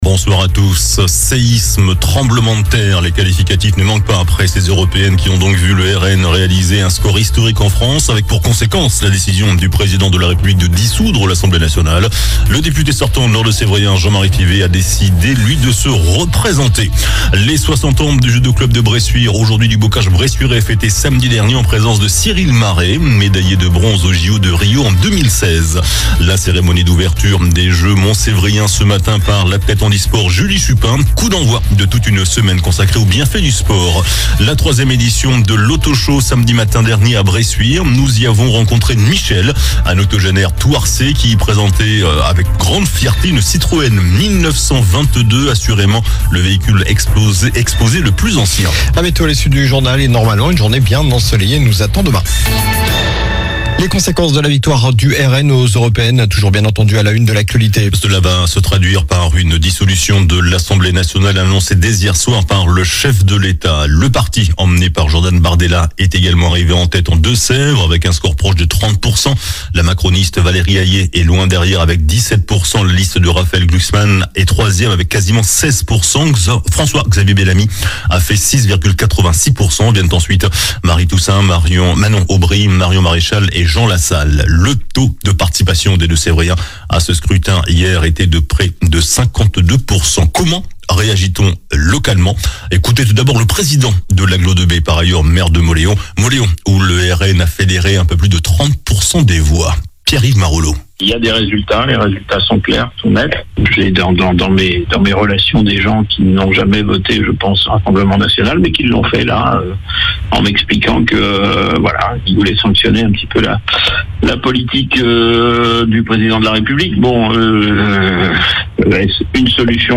JOURNAL DU LUNDI 10 JUIN ( SOIR )